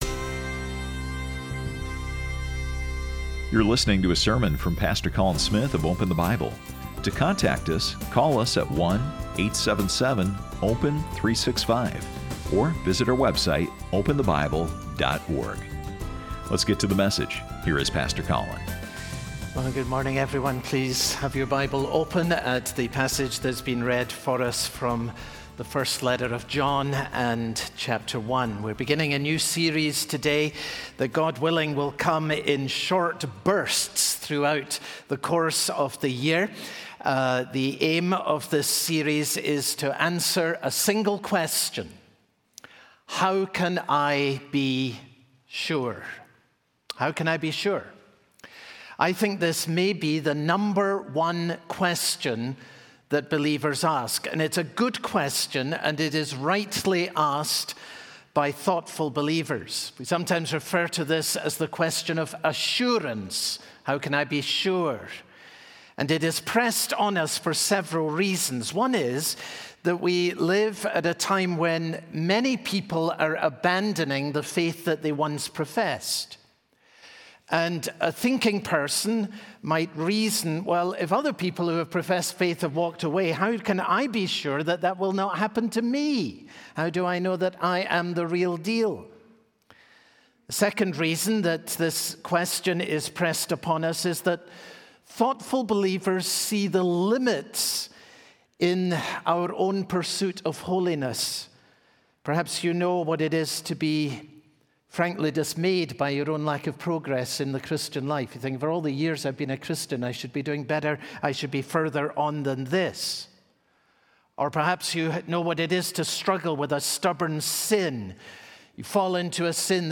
Sermon Details Date Feb 25